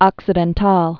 (ŏksĭ-dĕn-täl, ōksē-)